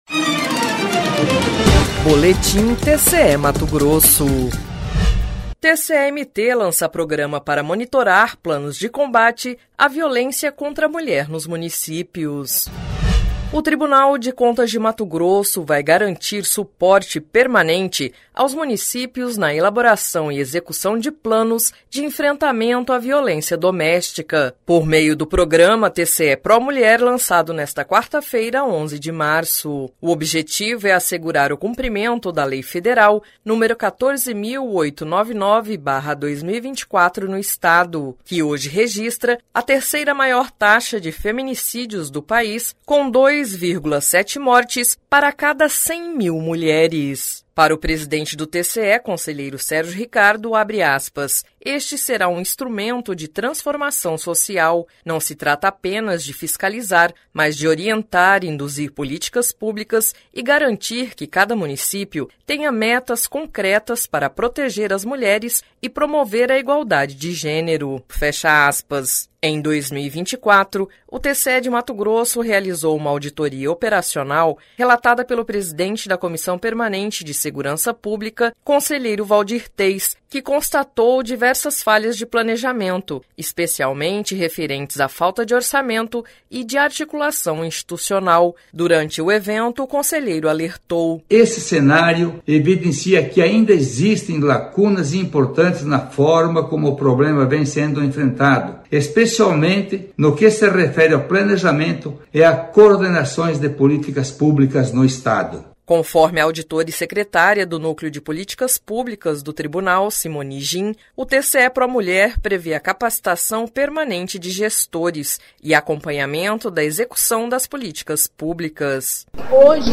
Sonora: Waldir Teis – conselheiro presidente da Copesp do TCE-MT
Sonora: Paula Calil - vereadora presidente da Câmara de Cuiabá
Sonora: Fernando Galindo - secretário-adjunto de Integração Operacional da Sesp-MT
Sonora: William Brito Júnior - procurador-geral do Ministério Público de Contas